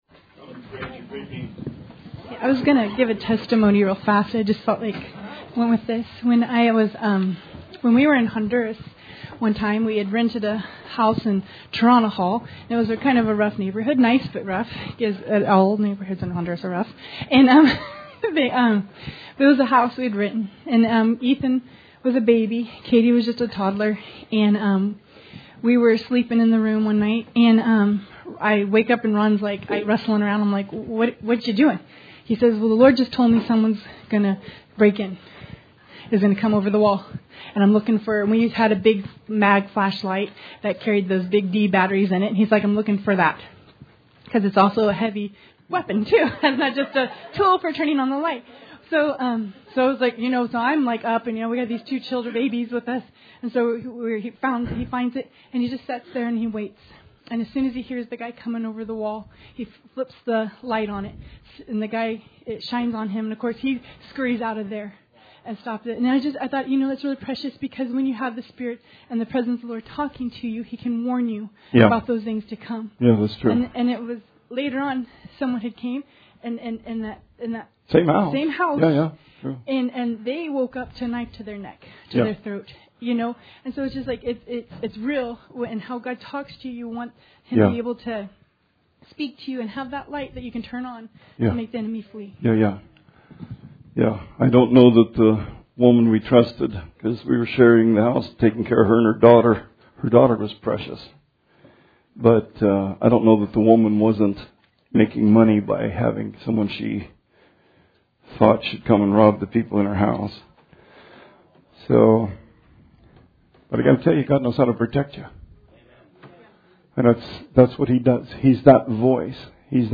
Sermon 8/2/20